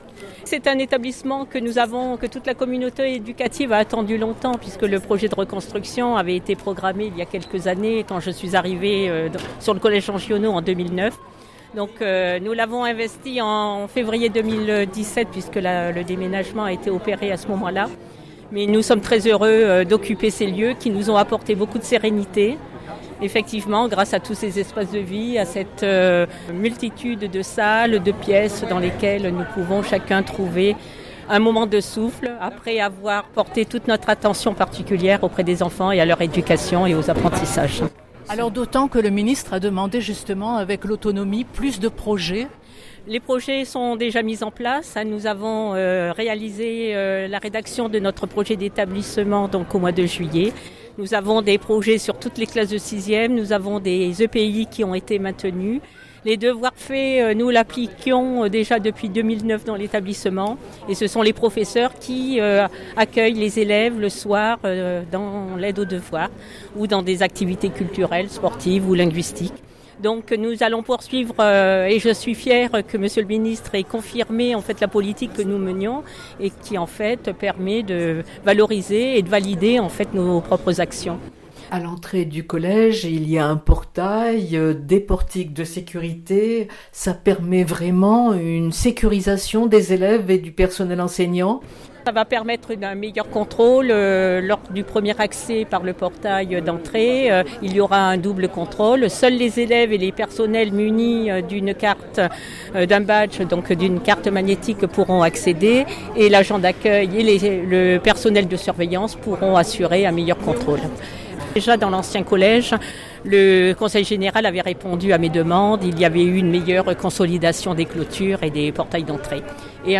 Puis, devant la presse, elle s’est félicitée de ce nouveau bâtiment malgré les délais de construction.